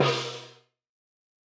Index of /90_sSampleCDs/300 Drum Machines/Boss Dr Pad - 1987/Boss Dr Pad Ableton Project/Samples/Imported
Gong-Short.wav